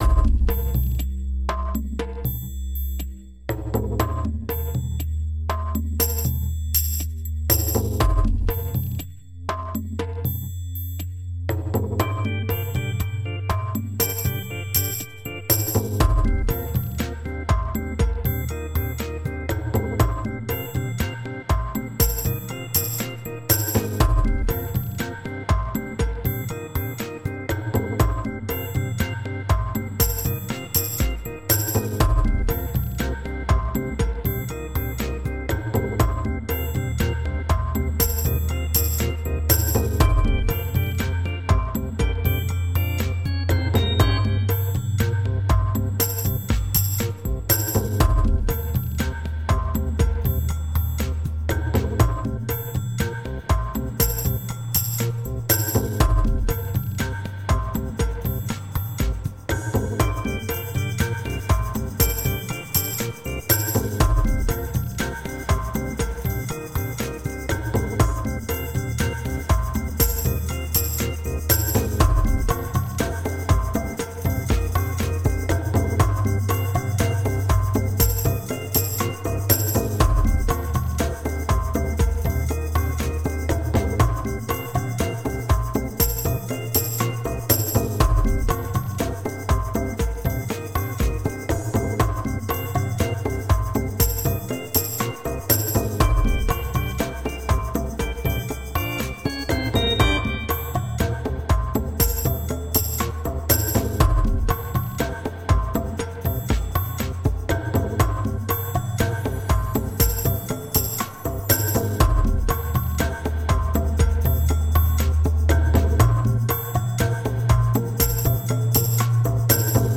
Underwater electronic soundscapes.
Tagged as: Ambient, Electronica, Background Mix, Space Music